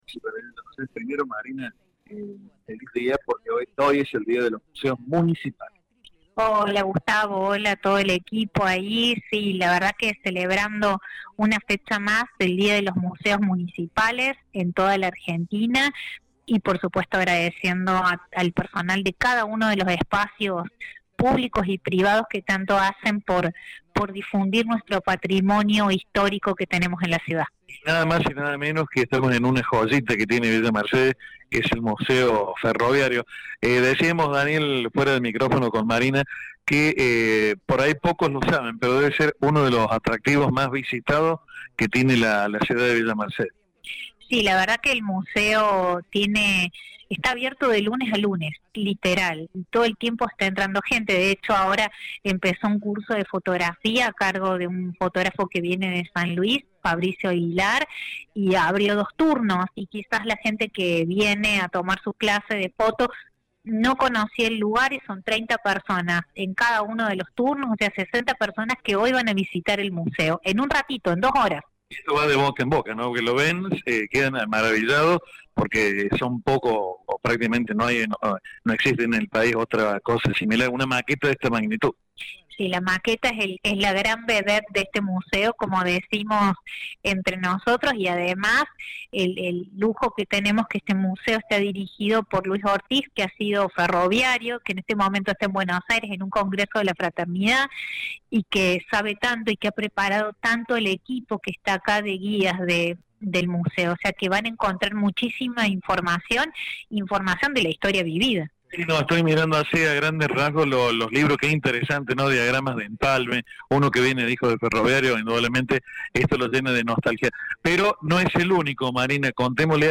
En comunicación con Radio La Bomba, desde el Museo Ferroviario, Marina Lara, subsecretaria de Cultura del municipio de Villa Mercedes, adelantó las actividades que se estarán llevando a cabo esta semana en los museos de la ciudad.